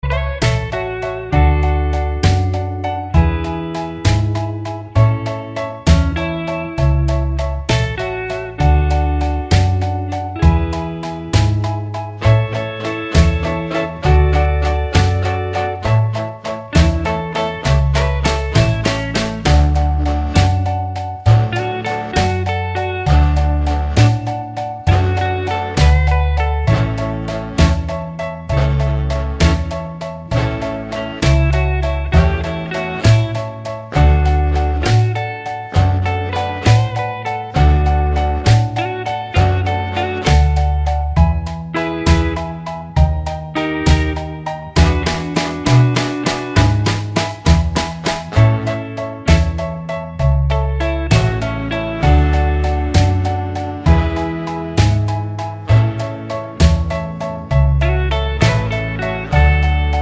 no Backing Vocals Oldies (Male) 2:17 Buy £1.50